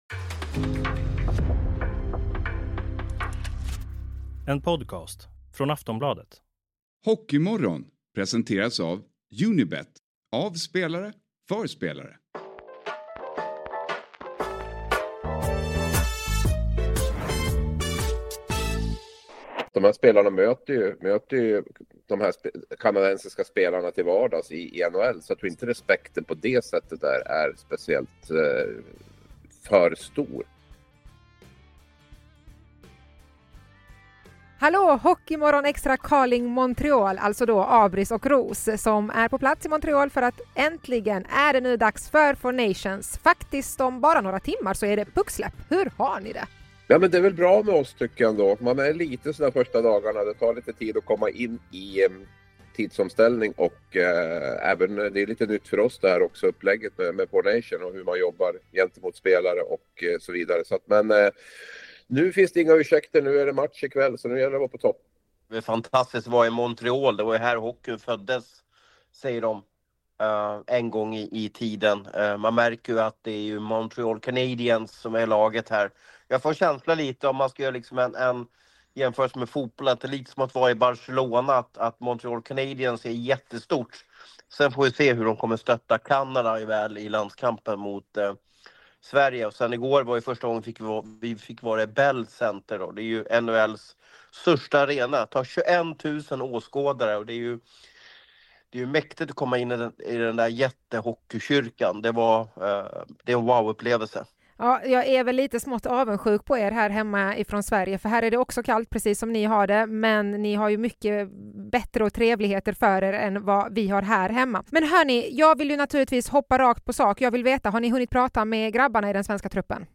En direktrapport från Montréal om allt inför Sveriges premiärmatch mot Kanada i Four Nations.